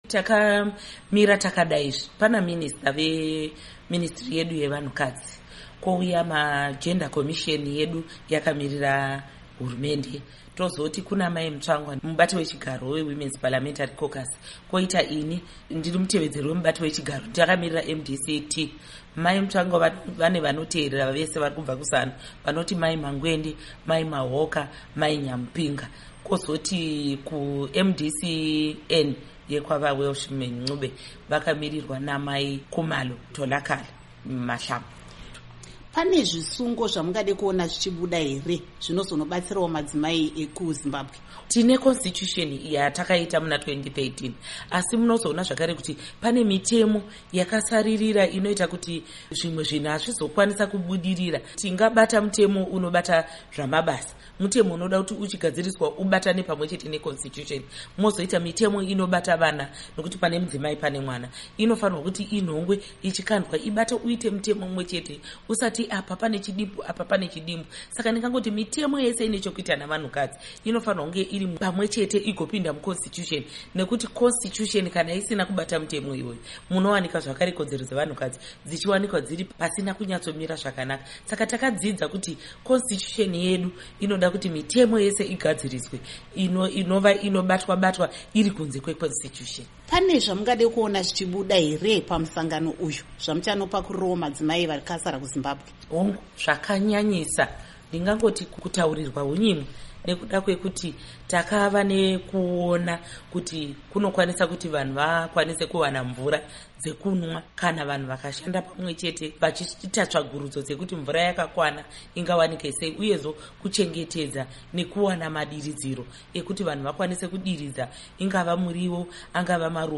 Hurukuro NaAmai Paurina Mpariwa Gwanyanya